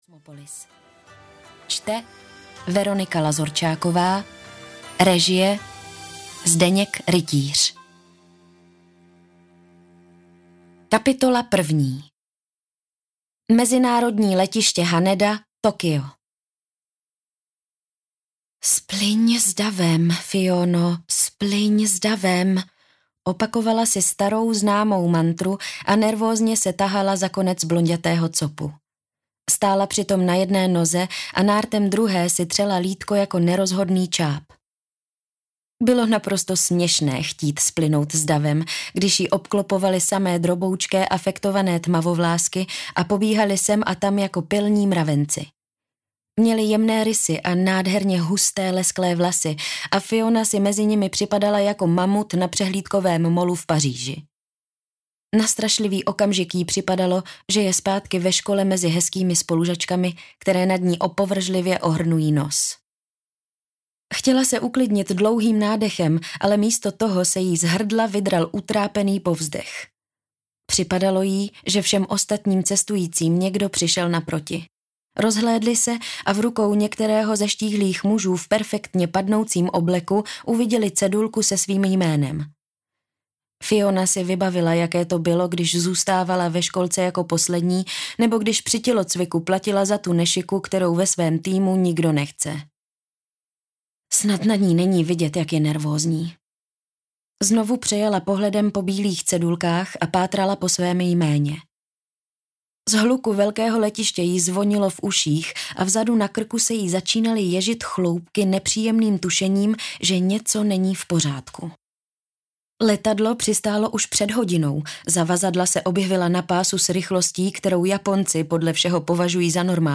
Čajovna v Tokiu audiokniha
Ukázka z knihy